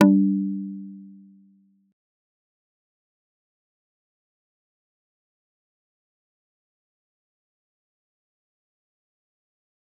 G_Kalimba-G3-pp.wav